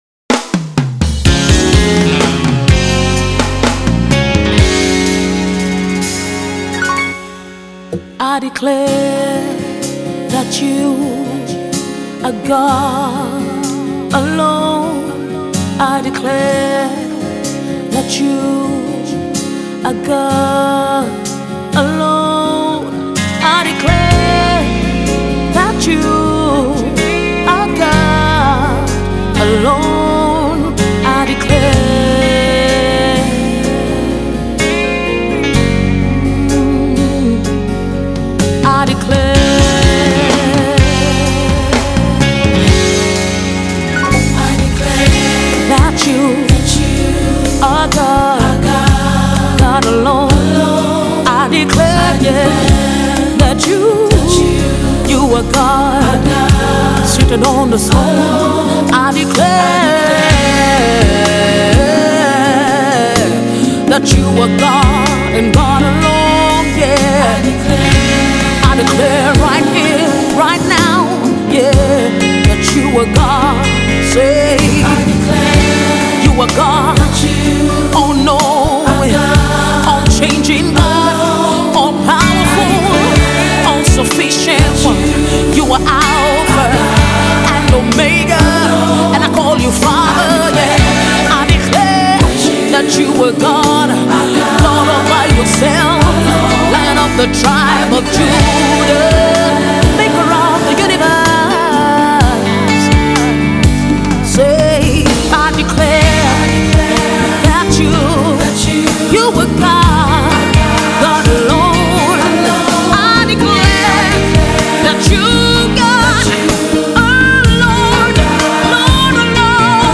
soulful worship song